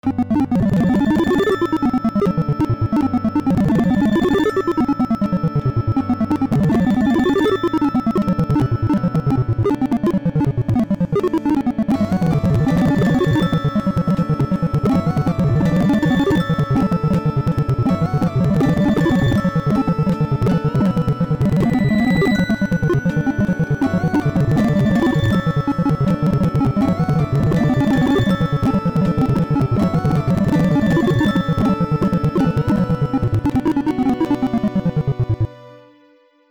How Nokia's Series 30 MIDI Simulator sounds with NVDA running LOL.